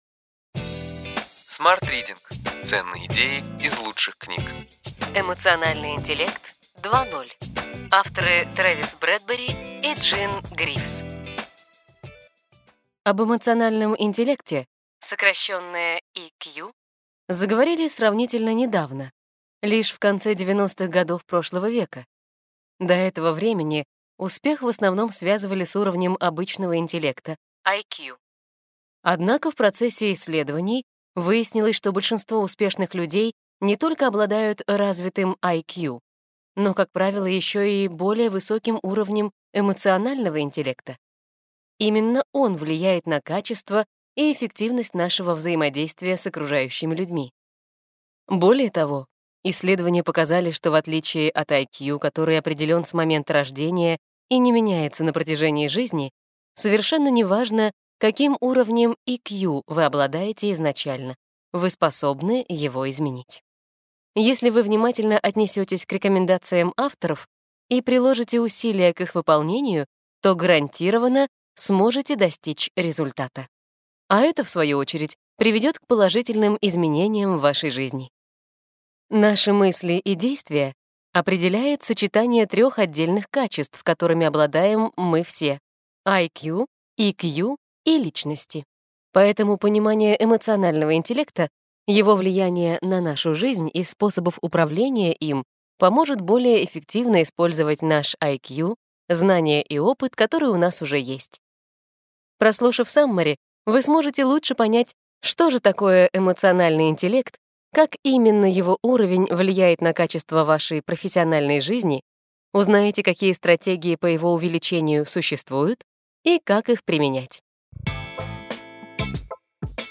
Аудиокнига Ключевые идеи книги: Эмоциональный интеллект 2.0. Тревис Бредберри, Джин Гривз | Библиотека аудиокниг